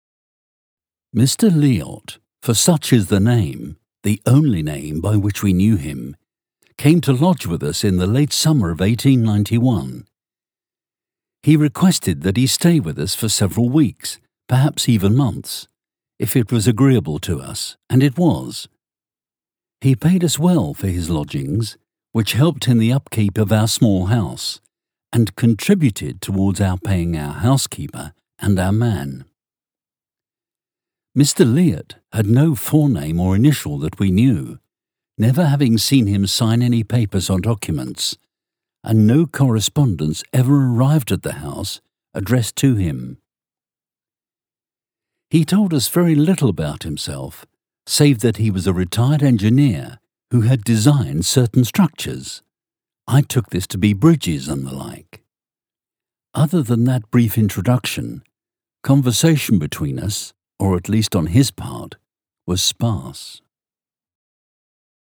Inglês (britânico)
Audiolivros
Equipamento de estúdio de alta qualidade. Gravação com microfones Audio-Technica e ADK, software Logic, interface de áudio Presonus, tratamento acústico Robinson em estúdio, etc.